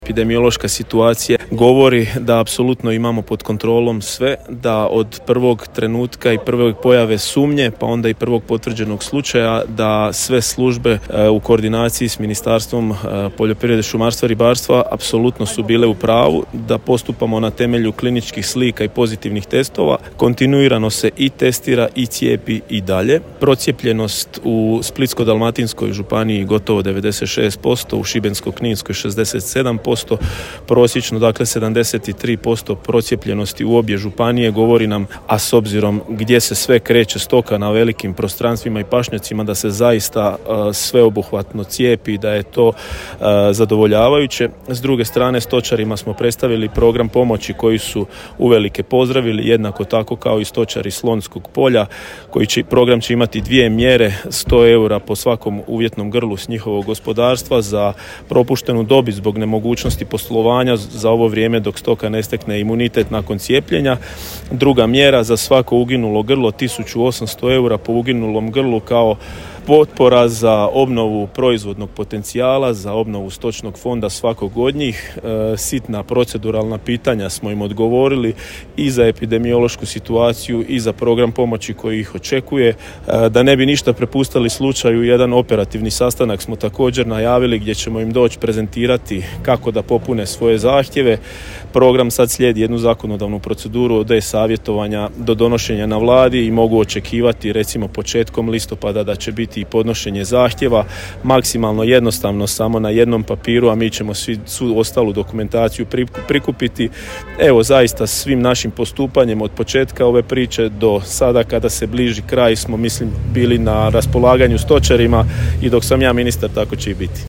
Epidemiološka situacija sa bedrenicom odnosno antraksom je pod kontrolom, kazao je nakon sastanka potpredsjednik Vlade i ministar poljoprivrede, šumarstva i ribarstva David Vlajčić: